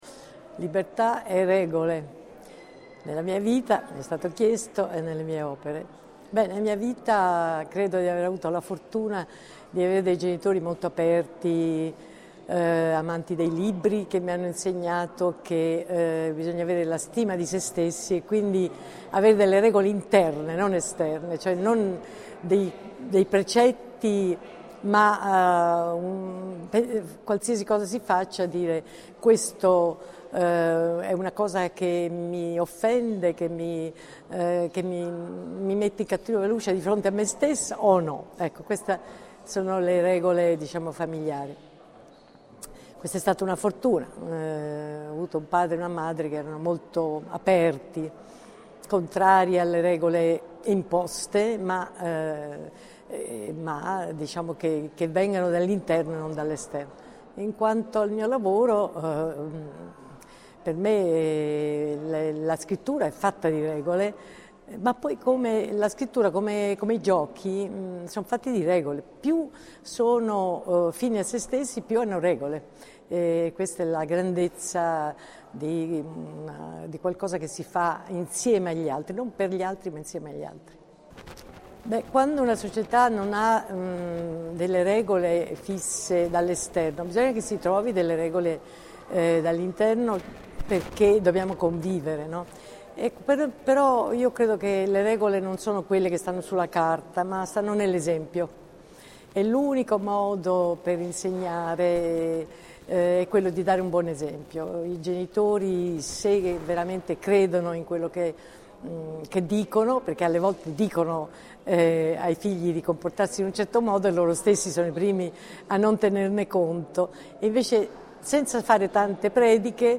INTERVISTA_DACIA_MARAINI.mp3